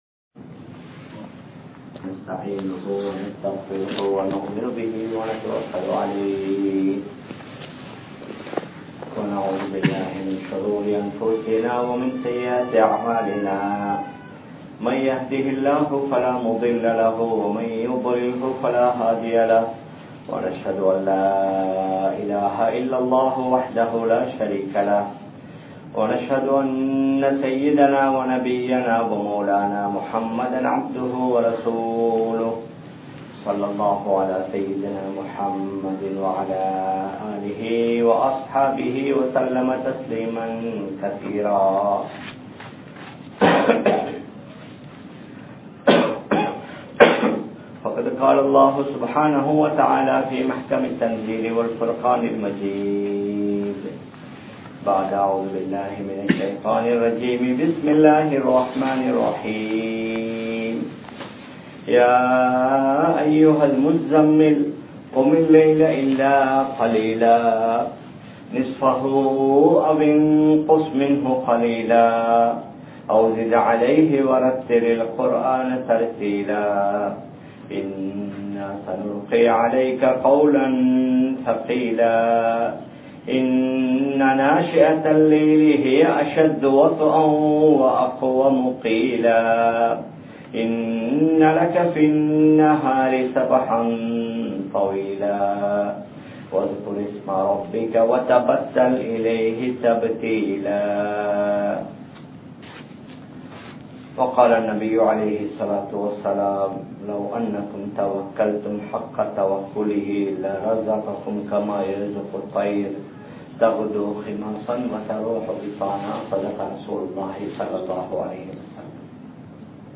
Dhunyaavin Veattri Dheenil Ullathu (துன்யாவின் வெற்றி தீணில் உள்ளது) | Audio Bayans | All Ceylon Muslim Youth Community | Addalaichenai